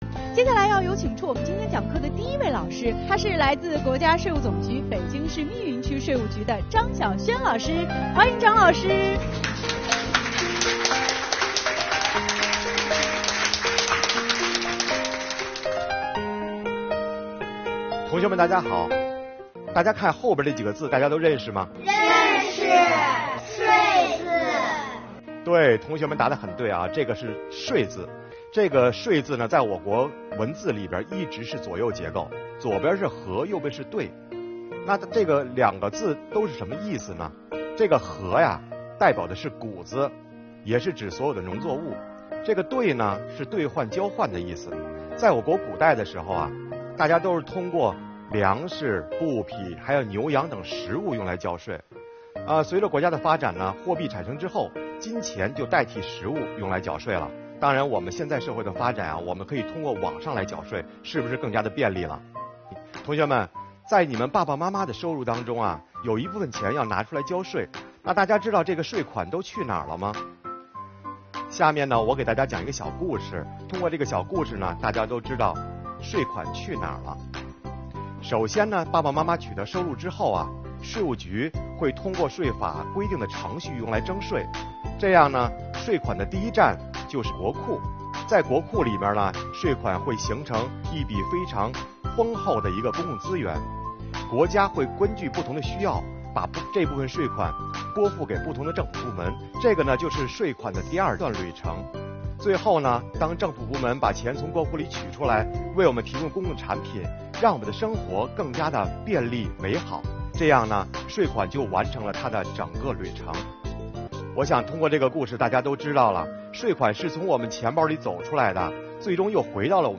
税收时时刻刻在我们身边，与我们的生活息息相关。税务局的老师用生动有趣的故事给同学们讲述税收去哪儿了↓↓↓